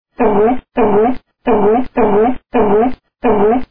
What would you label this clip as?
Foca Efeito Sonoro: Soundboard Botão